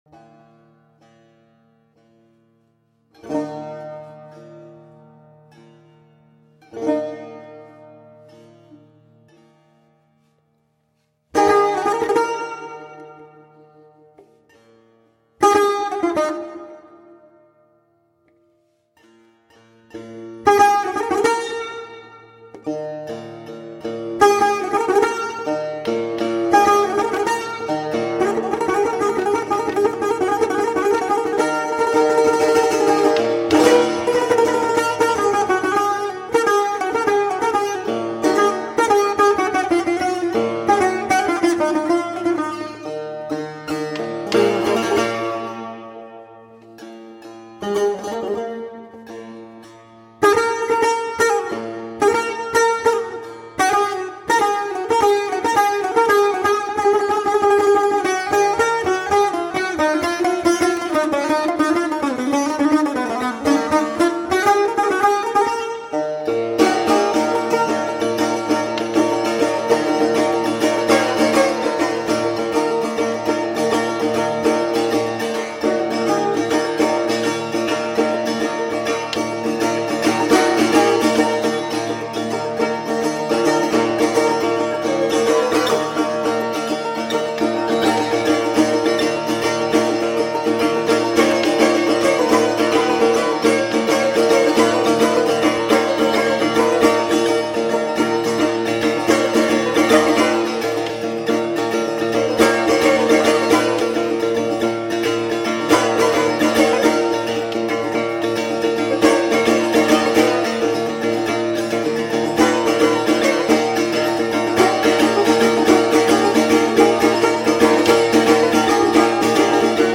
Classical styles of india and persia and oriental fusion.
Tagged as: World, Indian, World Influenced